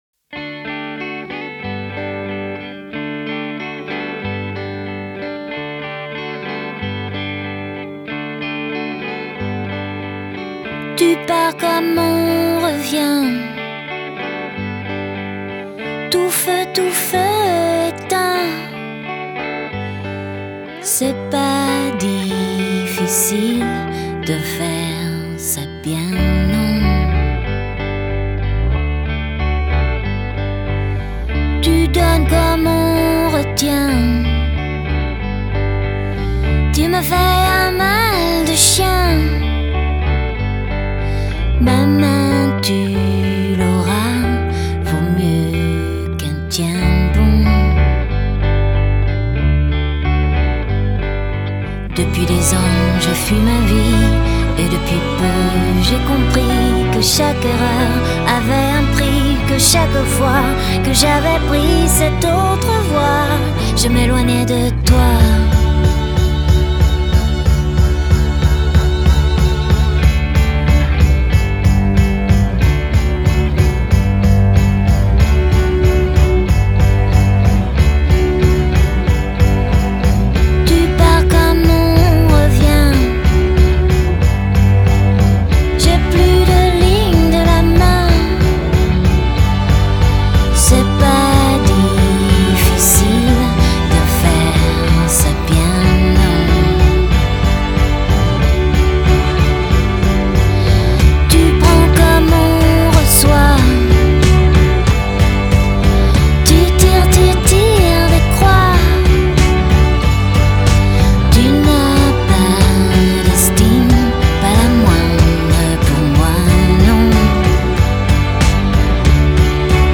Жанр: Pop, Chanson